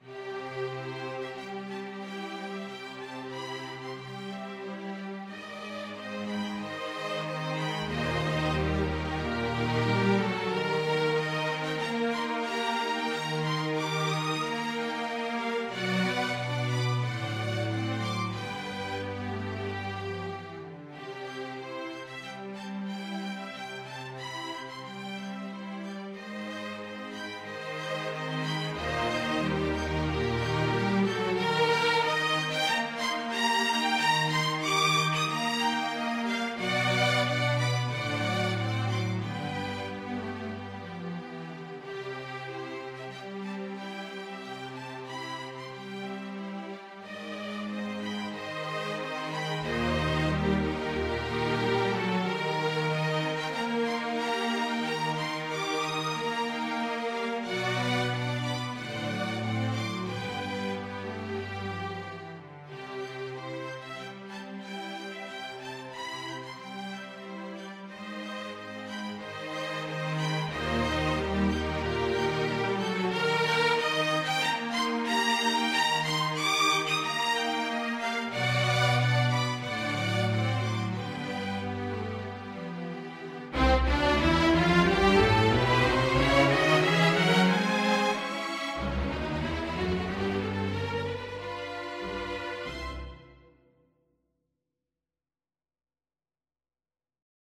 Violin 1Violin 2ViolaCelloDouble Bass
4/4 (View more 4/4 Music)
Andantino =92 (View more music marked Andantino)
Classical (View more Classical String Ensemble Music)